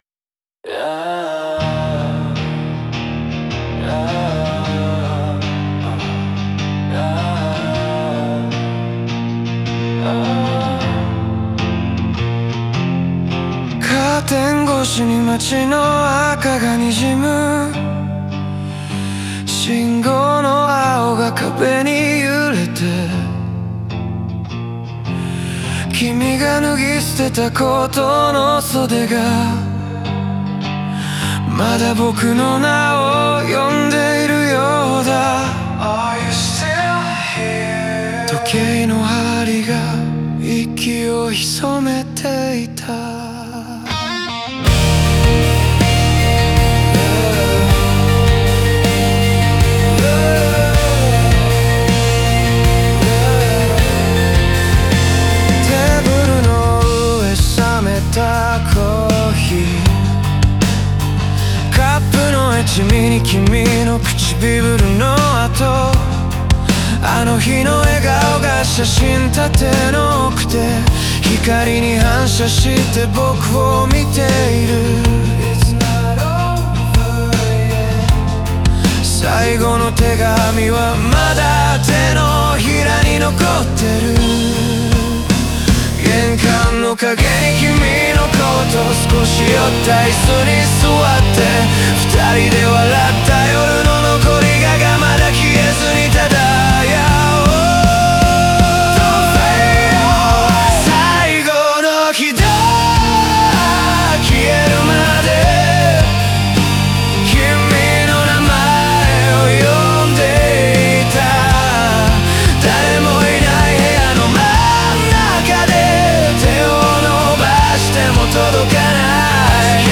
語りかけるような口調と優しいストリングスやバックコーラスが、悲しみと温かさを共存させる。